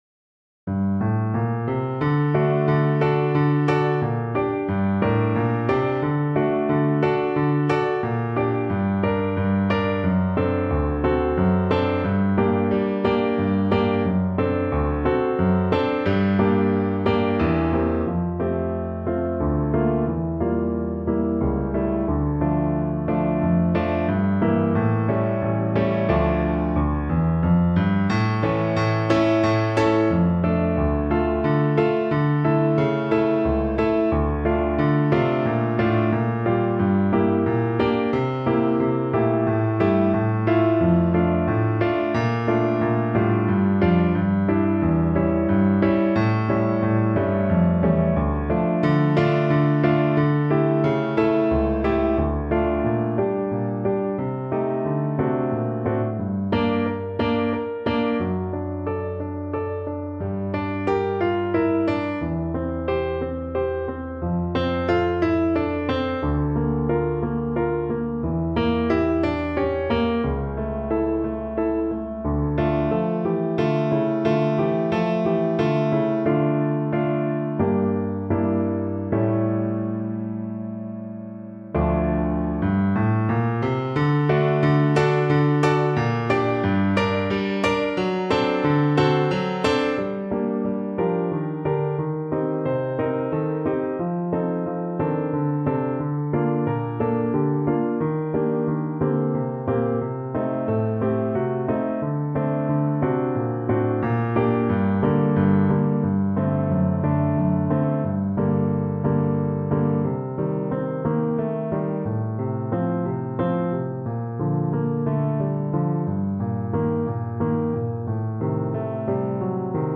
French Horn version
Allegro con spirito =138 (View more music marked Allegro)
3/4 (View more 3/4 Music)
French Horn  (View more Intermediate French Horn Music)
Classical (View more Classical French Horn Music)